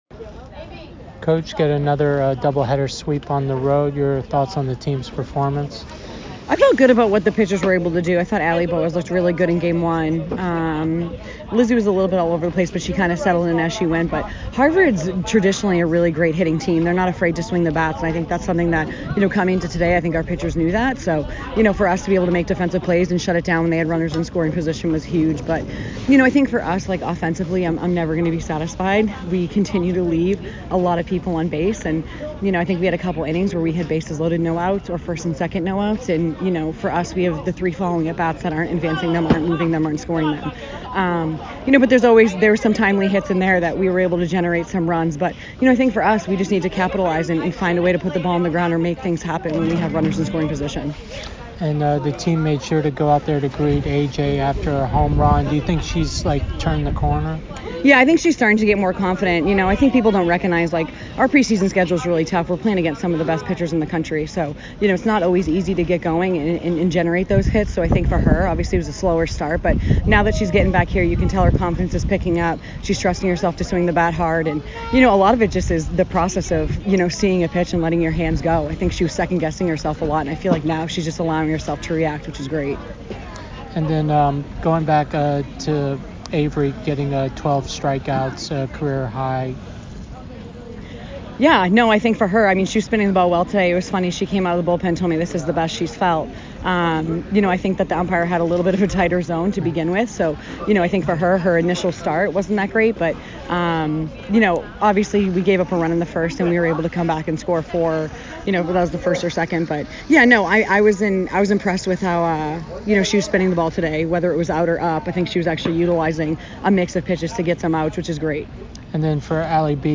Harvard Softball Postgame Interview